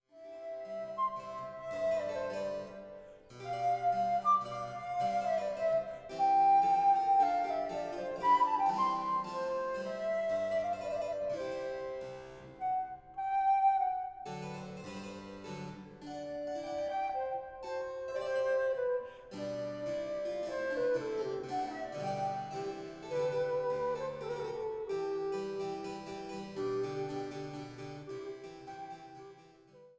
Sopran
Traversflöte
Viola da gamba
Cembalo